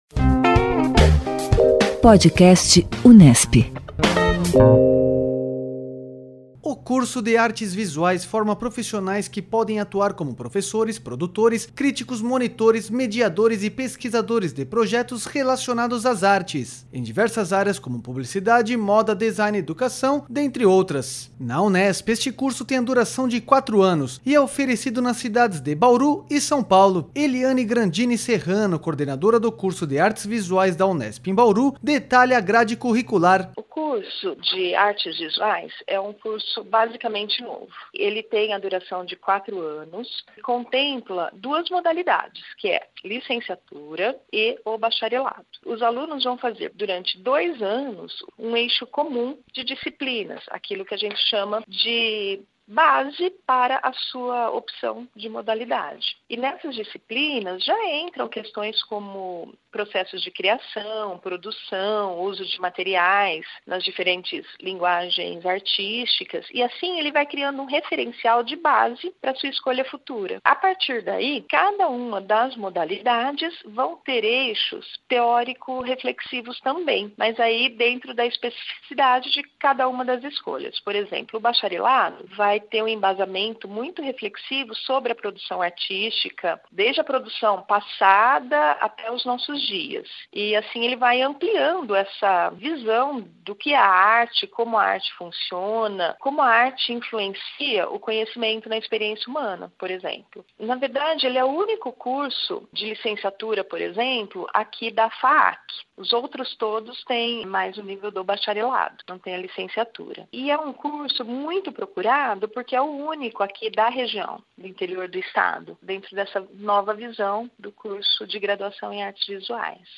O Pod Vestibular Unesp apresenta entrevistas com professores e coordenadores de cursos de graduação oferecidos pela Universidade.